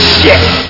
Amiga 8-bit Sampled Voice
takecover.mp3